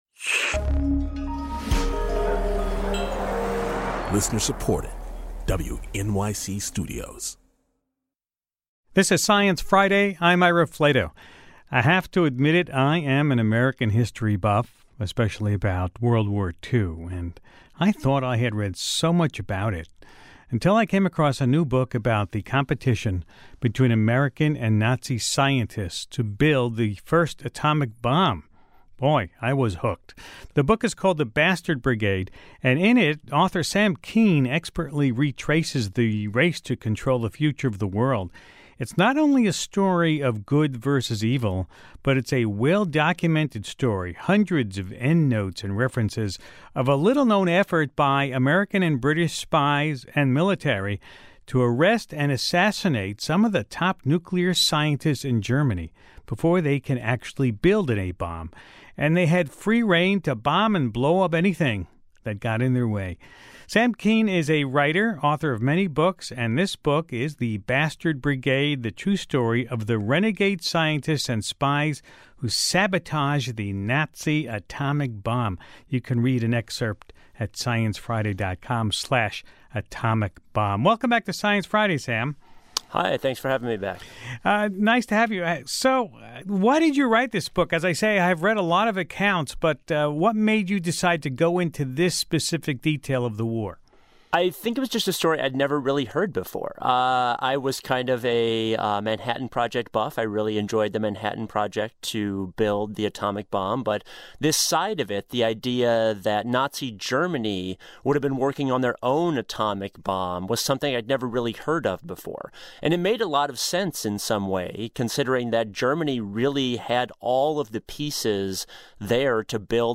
Science writer Sam Kean joins Ira to tell the high-stakes story written in his new book The Bastard Brigade: The True Story of the Renegade Scientists and Spies Who Sabotaged the Nazi Atomic Bomb.